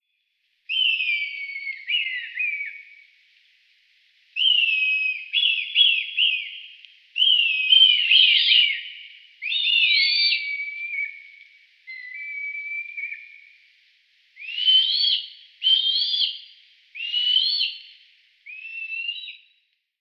Rotmilan.mp3